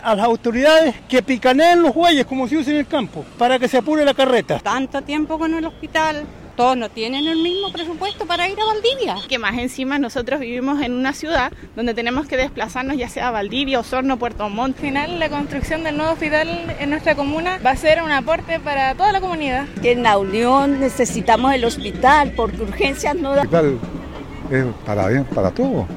Algunos de los manifestantes señalaron que están cansados de esperar el nuevo Hospital, lo que los obliga a trasladarse hasta otras ciudades para recibir atención, enfatizando que es una necesidad para los habitantes de la comuna.